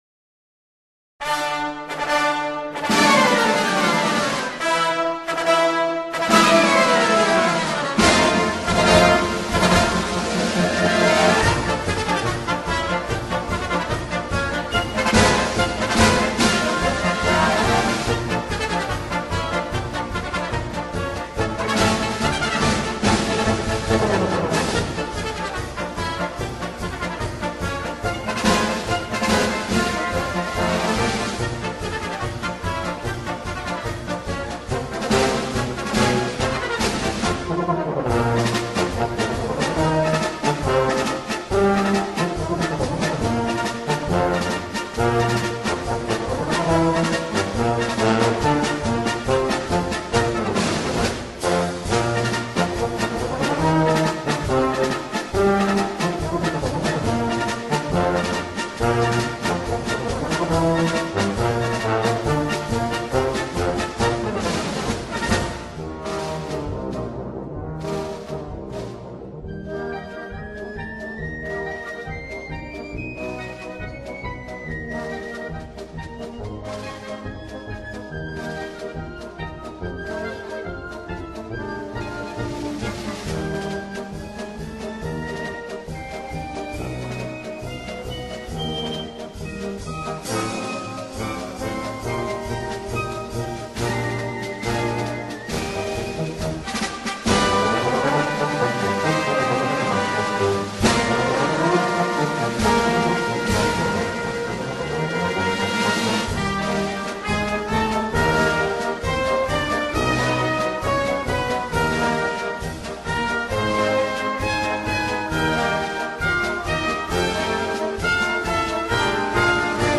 04-Musique-de-cirque.mp3